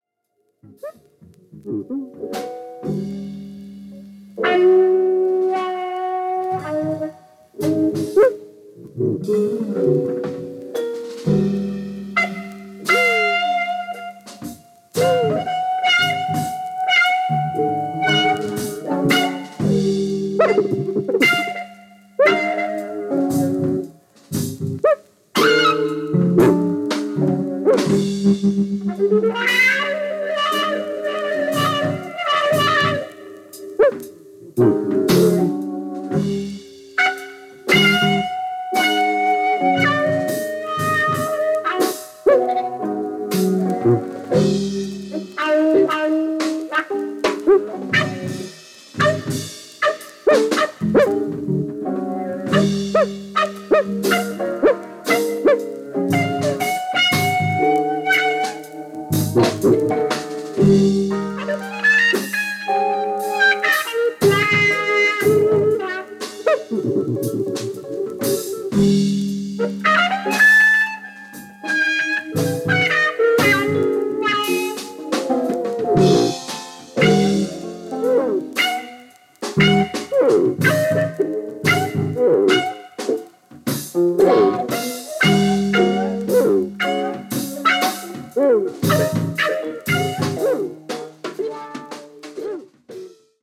スタジオ録音・ライブ録音を合わせて２枚組
エレクトリック感、プログレ感、サイケ感を感じるお勧め盤！！！